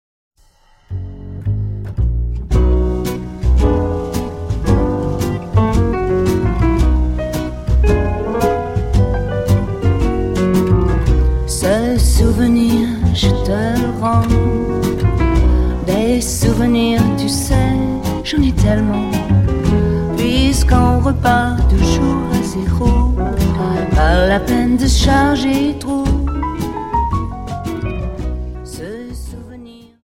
Dance: Slowfox Song